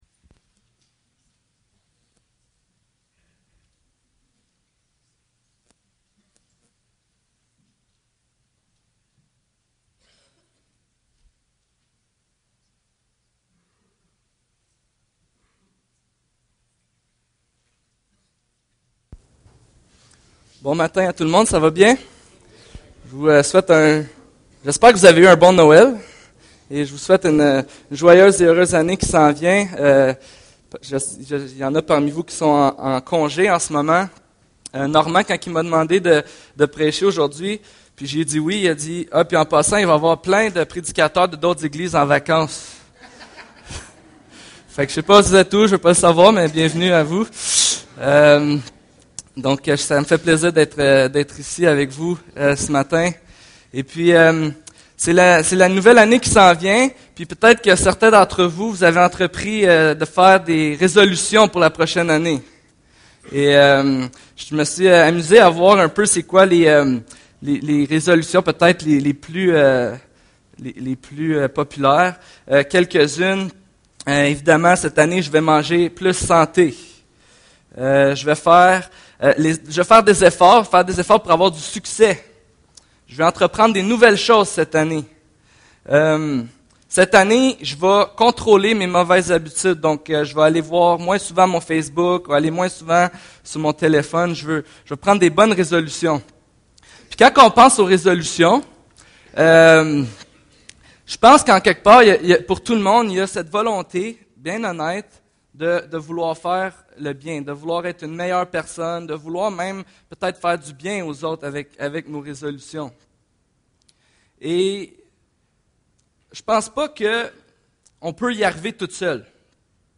1 Jean 1:5-2:2 Service Type: Célébration dimanche matin Trois mauvaises conceptions du péché dans ma vie accompagnée trois fois par l'évangile.